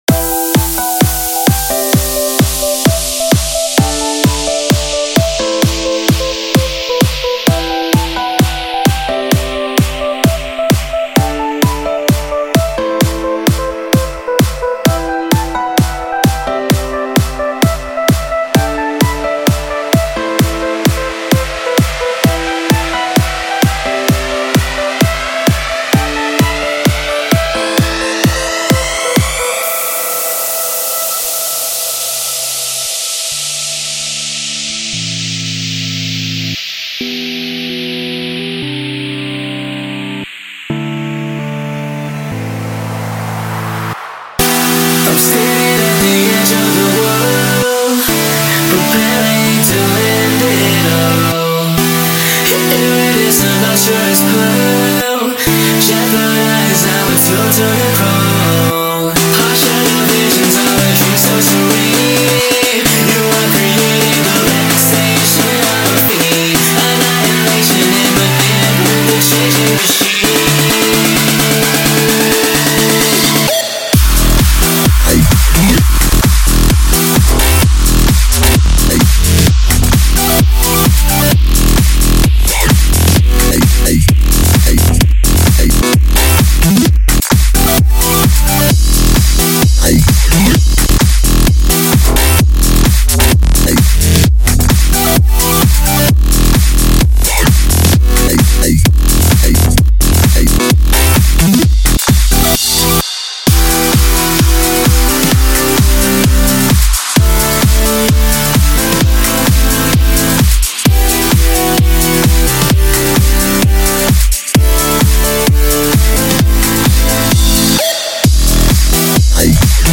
Genre: Complextro Tempo: 130bpm Key: E